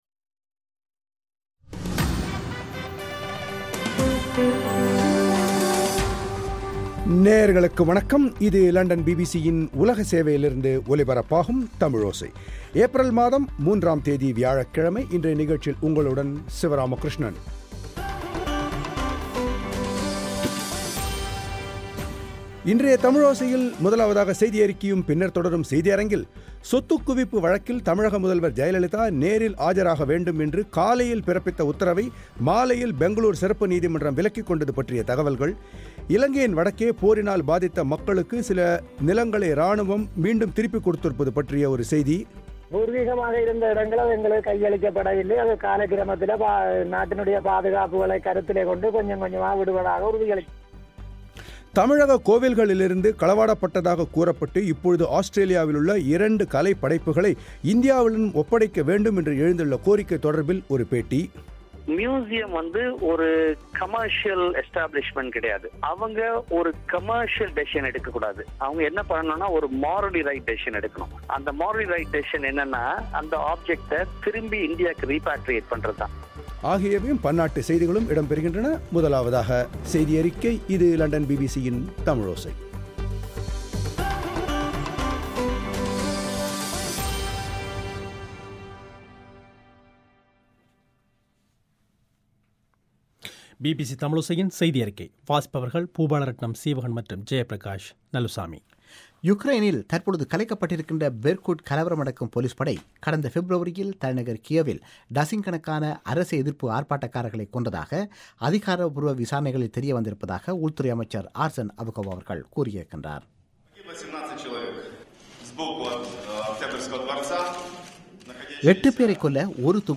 தமிழக கோவில்களிலிருந்து திருடப்பட்டதாகக் கூறப்பட்டு இப்போது ஆஸ்திரேலியாவிலுள்ள இரண்டு கலைப்படைப்புகளை இந்தியாவிடம் ஒப்படைக்க வேண்டும் என்று எழுந்துள்ள கோரிக்கை தொடரிபில் ஒரு பேட்டி.